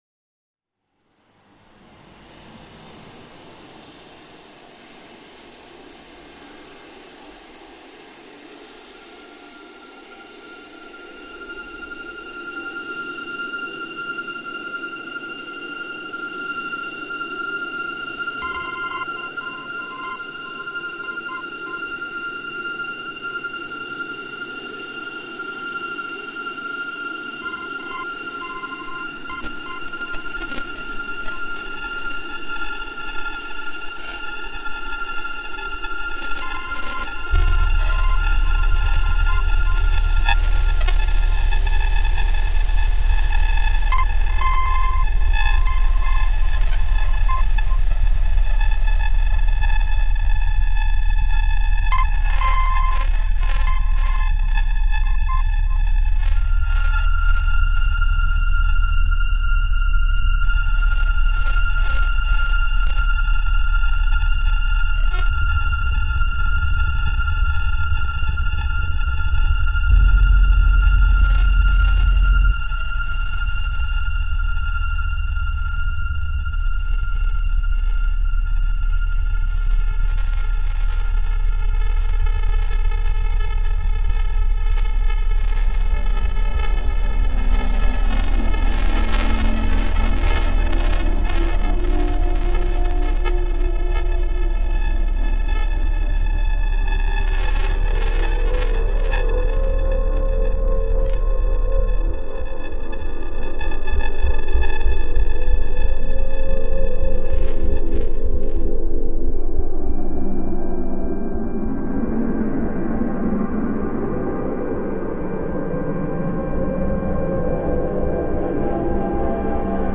‘Tre Visi’ explores the sonic identity of Treviso, Italy, anchoring itself in a field recording of the city’s Fontana delle Tre Facce (Fountain of the Three Faces).
The piece explores the ambiguity of the city’s history and the debate between its potential ‘Three Hills’ Latin etymology and its Celtic origins. The production includes processed samples from a Celtic-like vocal melody alongside the fountain's texture, creating a new space for three voices to navigate an expansive aural space of possibilities.